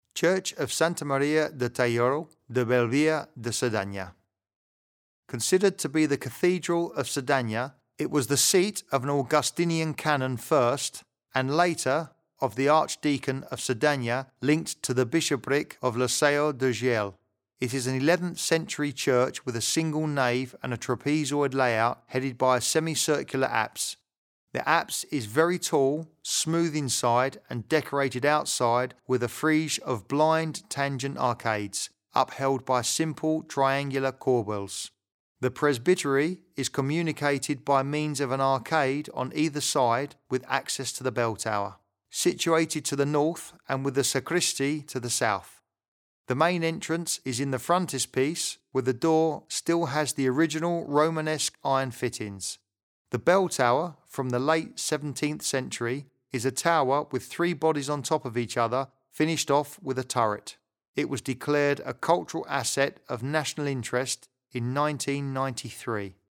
Escucha la locución en la que te explicamos cómo es la iglesia de Santa Maria de Talló
locucio-angles-santa-maria-tallo.mp3